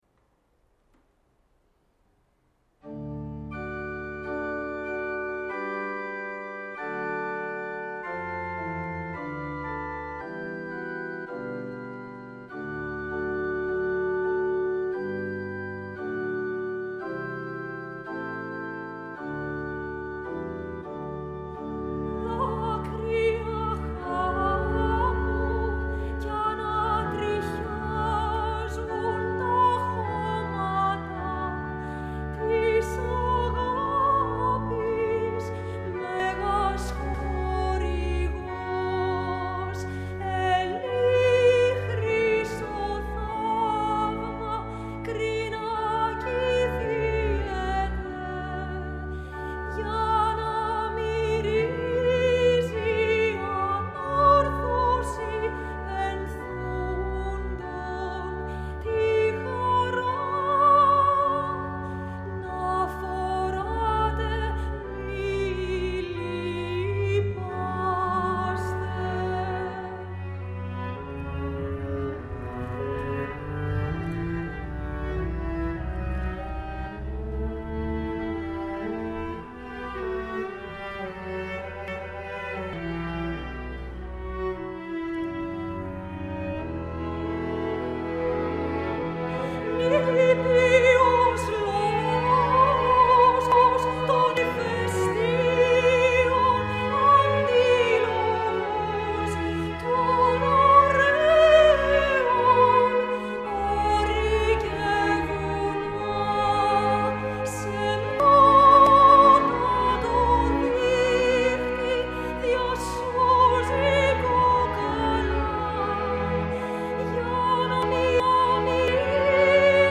Παραδοσιακό
“Δάκρυα Χάμου” για Σοπράνο – Ορχήστρα Εγχόρδων και Όργανο (live)
Ορχήστρα Φεστιβάλ Πάτμου